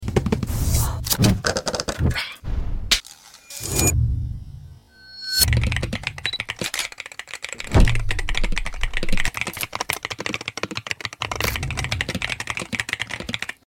My new main gaming keyboard? sound effects free download